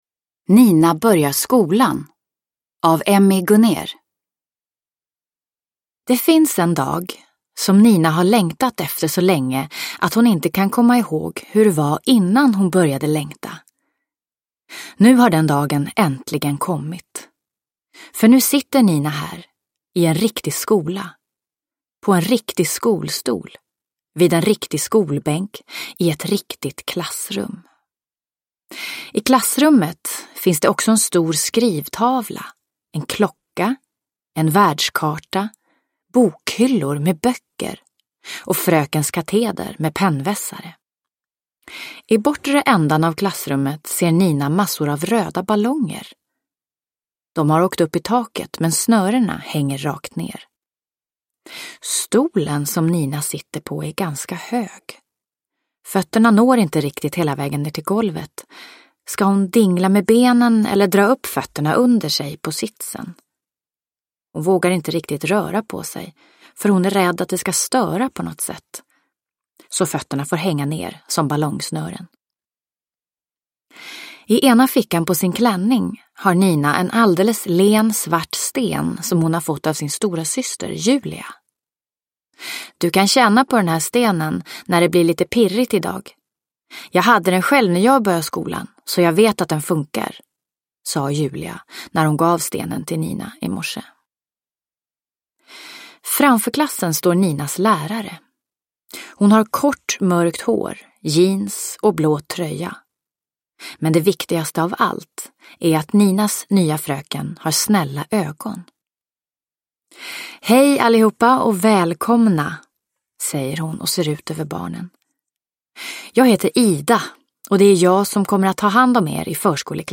Nina börjar skolan – Ljudbok – Laddas ner